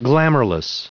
Prononciation du mot glamourless en anglais (fichier audio)
Prononciation du mot : glamourless